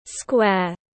Square /skweər/